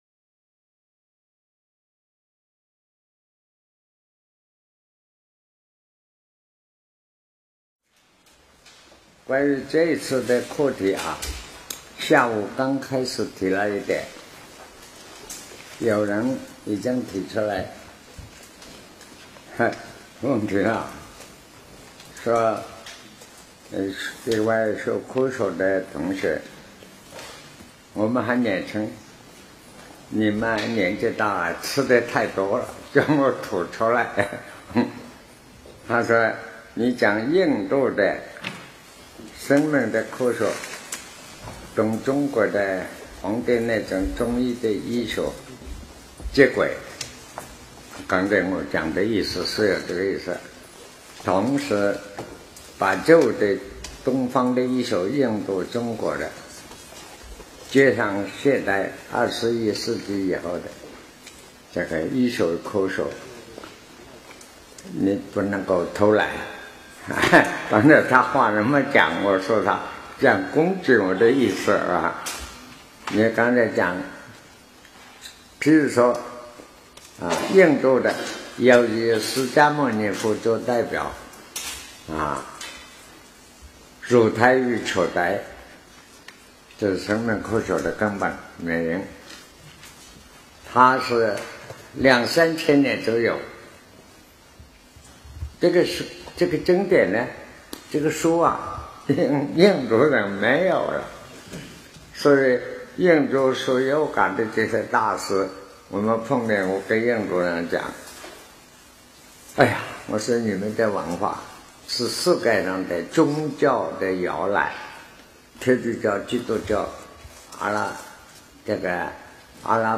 南怀瑾先生讲《黄帝内经》02